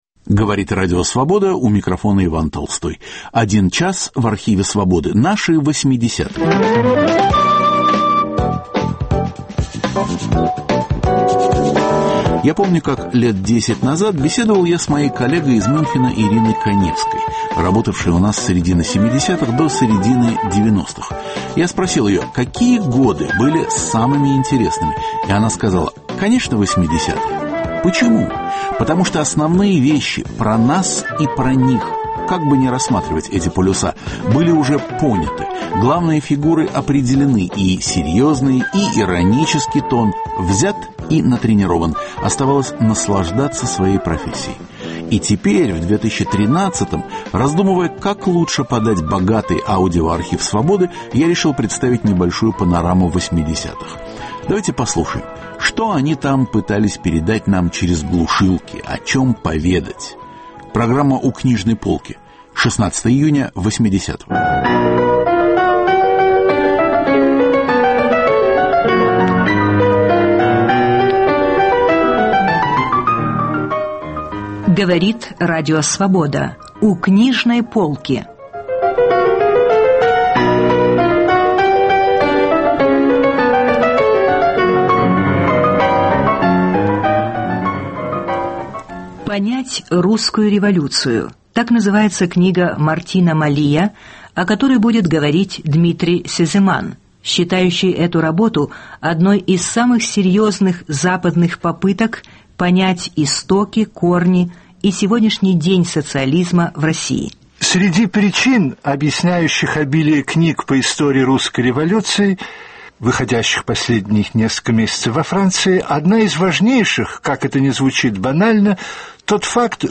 Каждый выпуск программы сопровождают новые музыкальные записи.